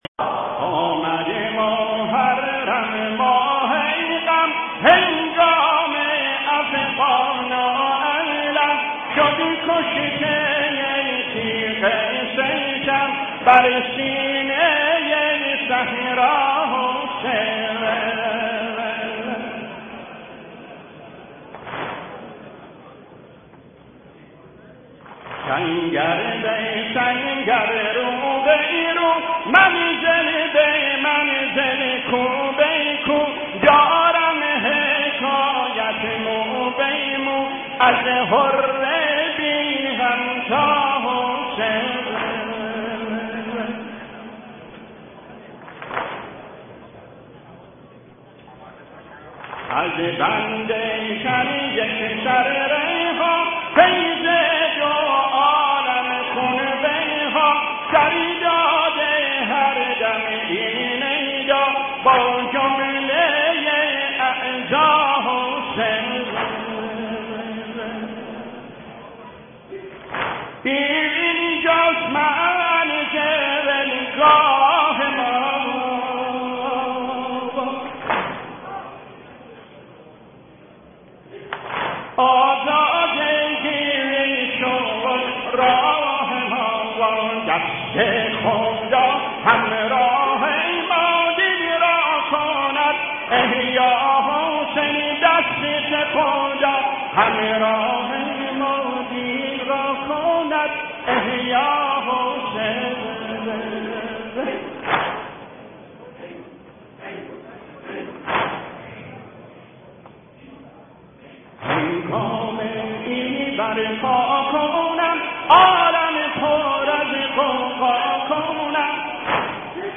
برچسب ها: نوحه بوشهری ، دانلود